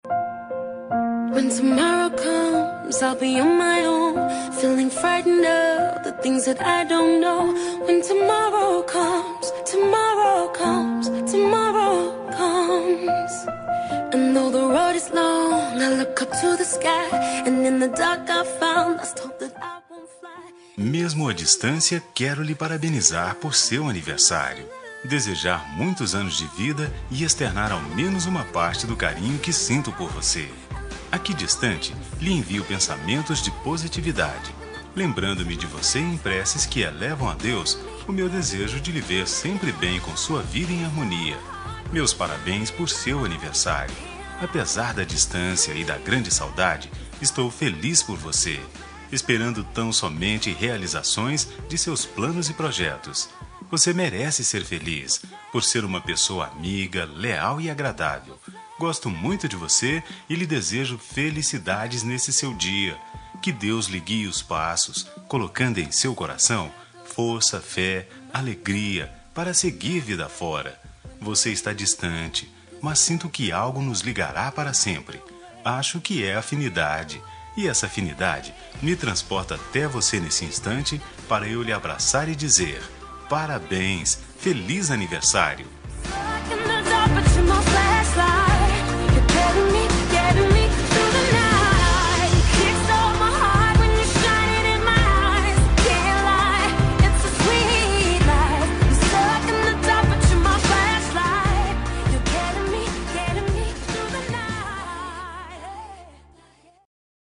Aniversário Distante – Pessoa Especial – Masculina – Cód: 8893